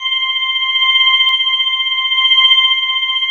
90 ORGAN  -L.wav